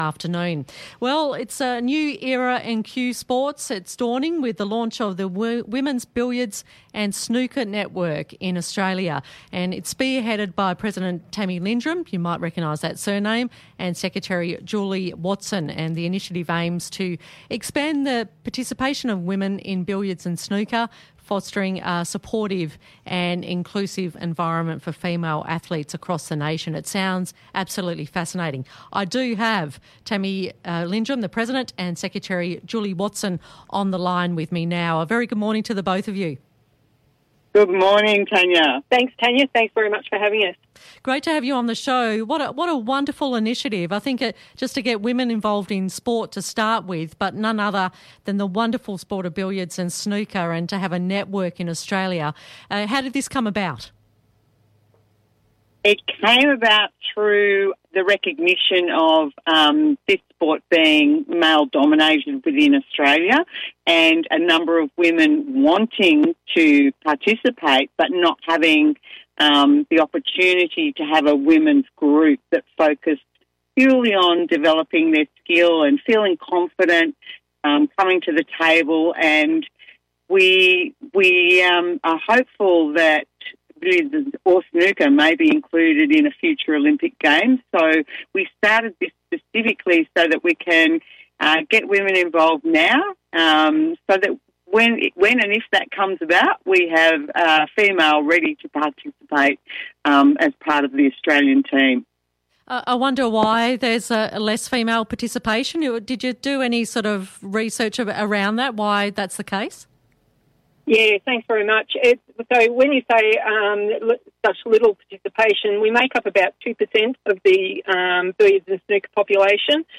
WBSN Inc. was proud to be featured on local radio yesterday, sharing our vision for growing women’s participation in cue sports across Victoria and beyond.
The interview provided an opportunity to discuss why WBSN Inc. was formed, the importance of structured development pathways for women and girls, and the momentum building through initiatives such as our regional workshops and upcoming tournaments.